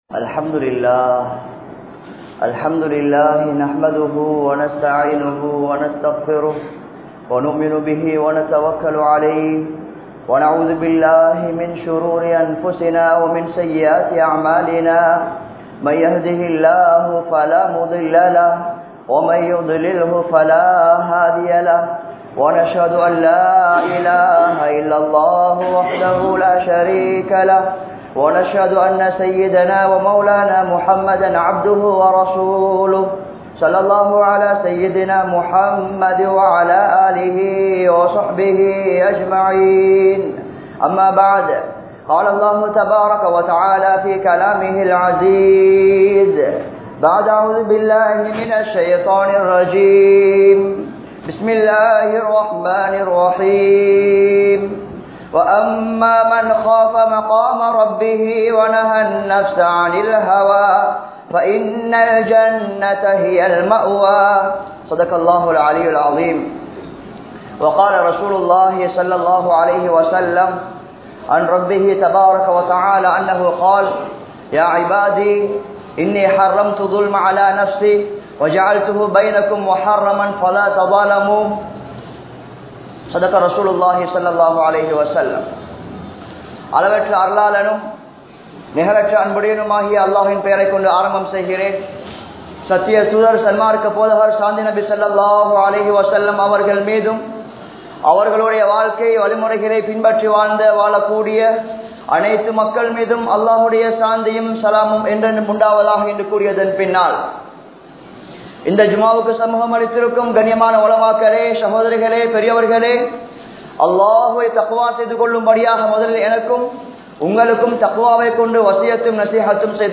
Aniyaayaththin Vifareetham (அநியாயத்தின் விபரீதம்) | Audio Bayans | All Ceylon Muslim Youth Community | Addalaichenai
Jamiul Qarni Jumua Masjidh